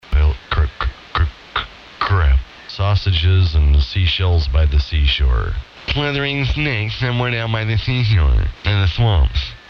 Hi-Fi ESSB Voodoo Audio Amateur Radio Station